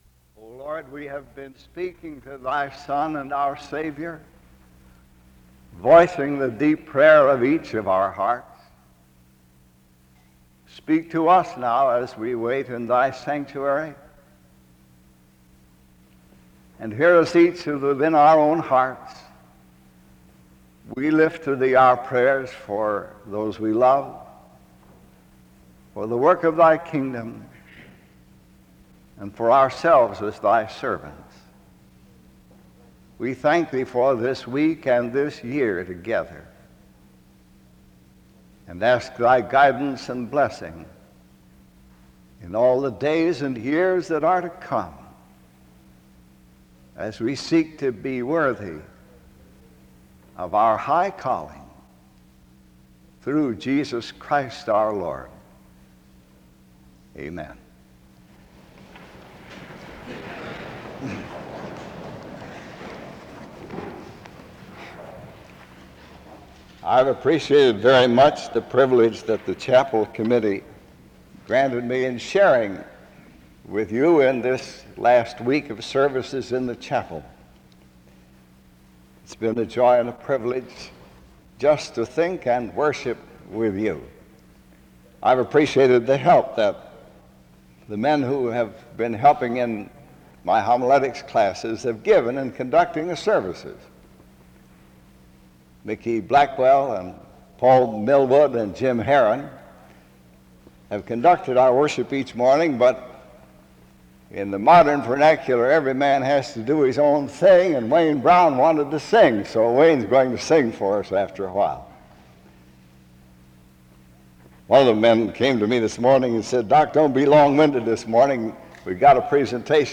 The service begins with a prayer (0:00-1:02).
He closes in prayer (22:50-24:20).